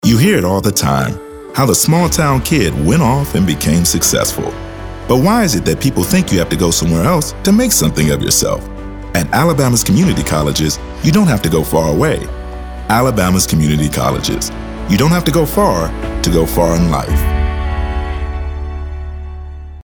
African American, announcer, confident, conversational, cool, Deep Voice, friendly, genuine, informative, inspirational, middle-age, midlife, motivational, narrative, neutral, sincere, smooth, storyteller, Straight Forward, thoughtful, warm